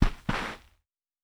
Astroturf Sudden Stop.wav